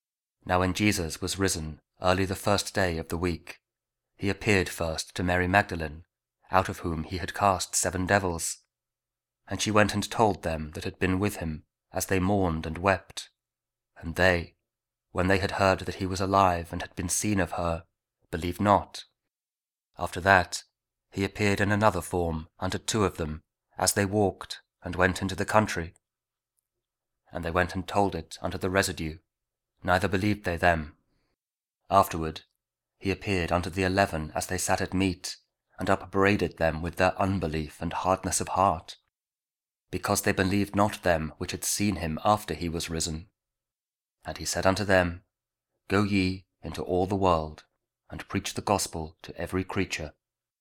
Mark 16: 9-15 Audio Bible KJV | King James Audio Bible | Daily Verses